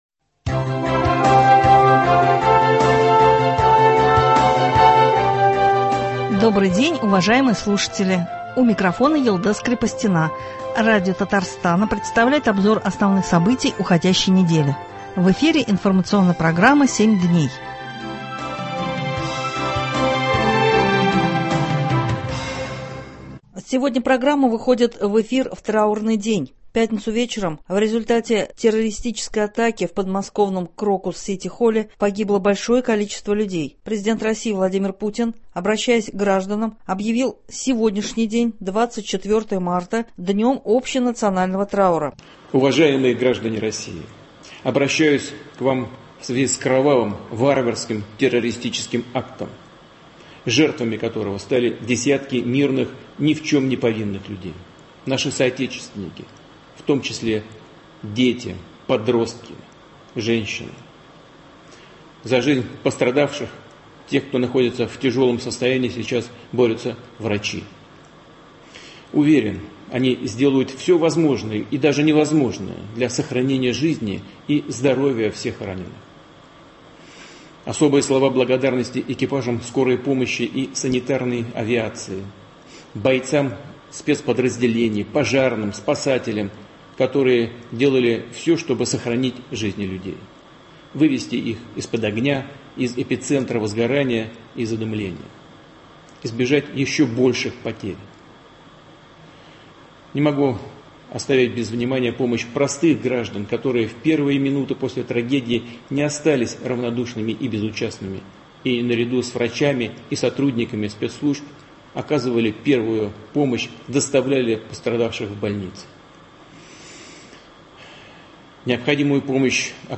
Обзор событий недели.